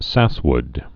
(săswd)